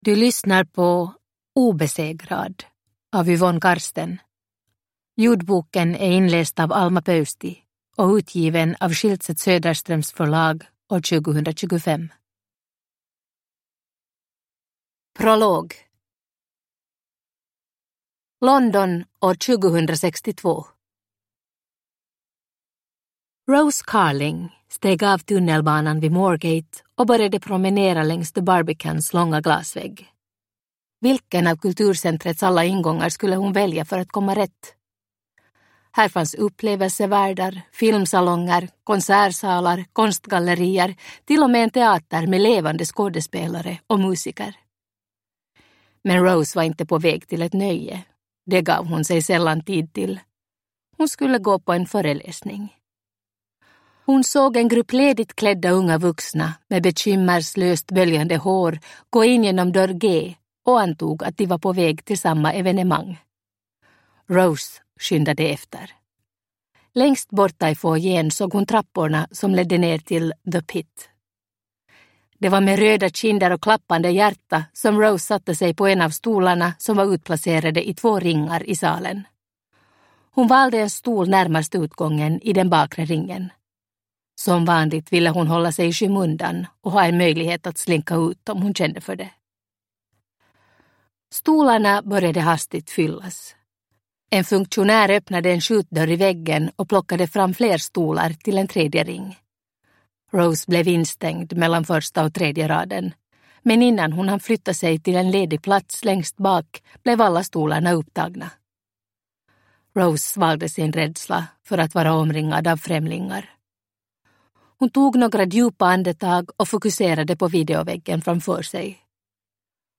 Obesegrad – Ljudbok
Uppläsare: Alma Pöysti